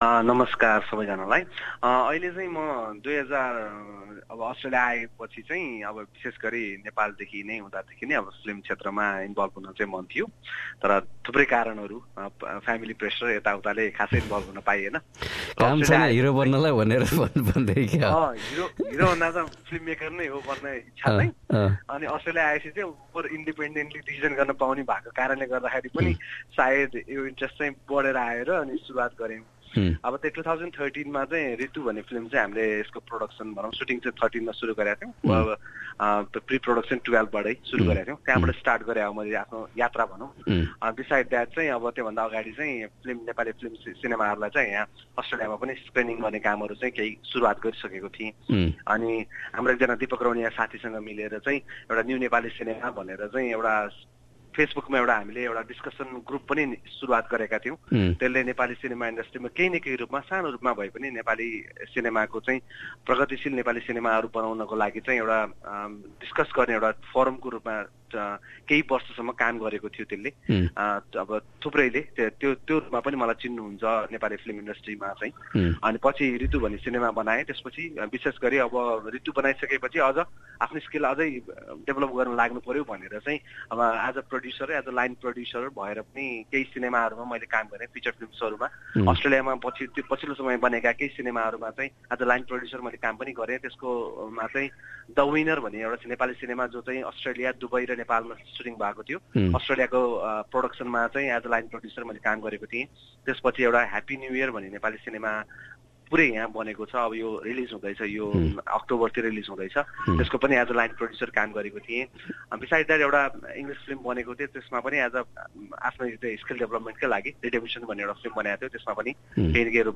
यसबारे एसबीएस नेपालीसँग कुरागर्दै।